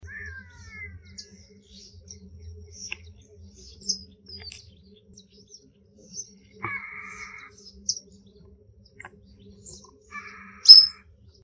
Harris´s Hawk (Parabuteo unicinctus)
Life Stage: Adult
Detailed location: Laguna Guatraché
Condition: Wild
Certainty: Observed, Recorded vocal